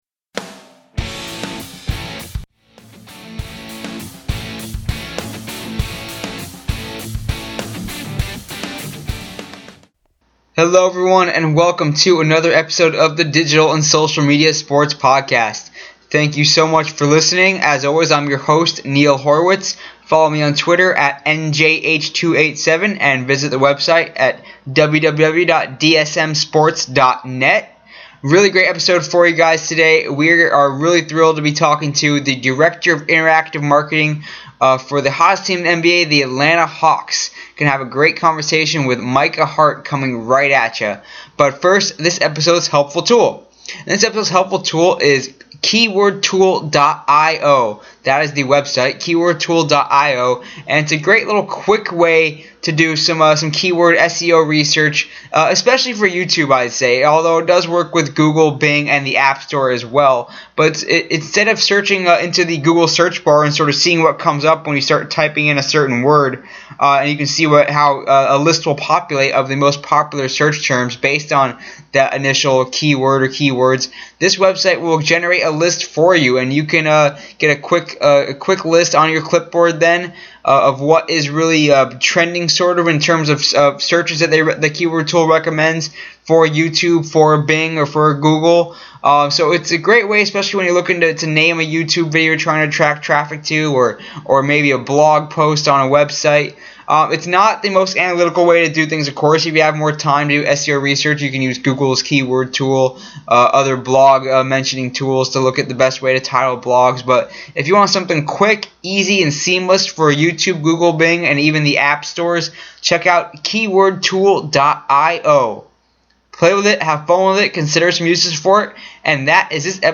Show format contains separate parts.